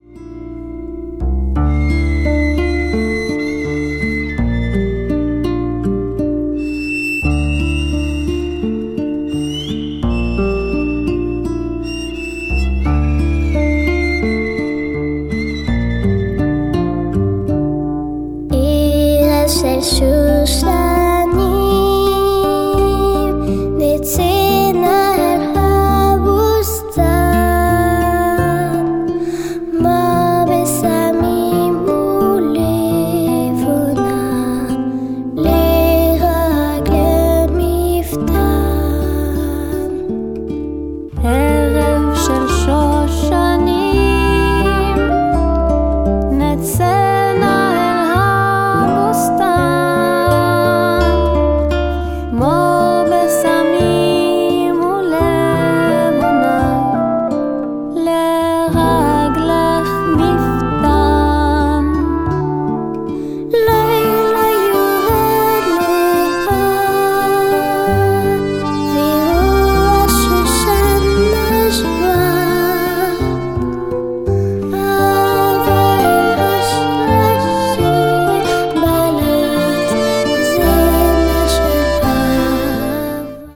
enthält 28 jüdische Kinderreime und Wiegenlieder in Arabisch